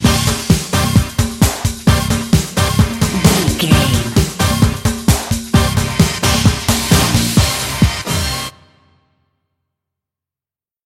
Fast paced
Ionian/Major
Fast
synthesiser
drum machine